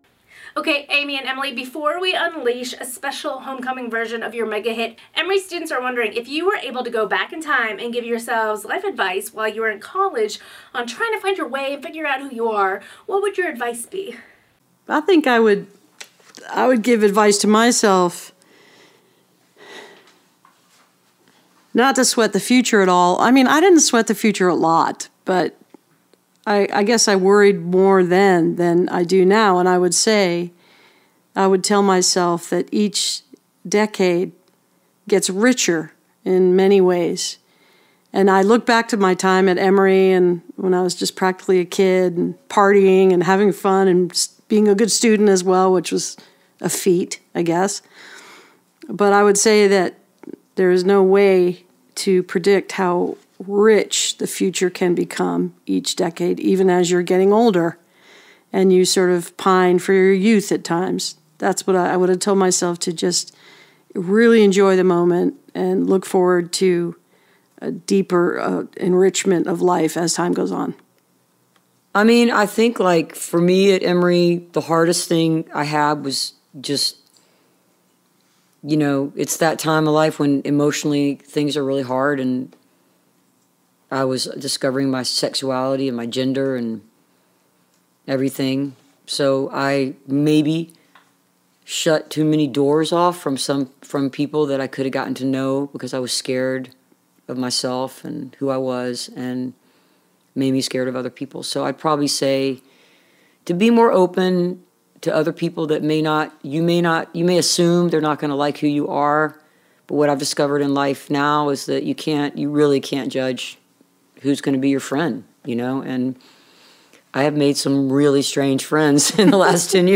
(captured from the youtube livestream)
11. interview (indigo girls) (2:46)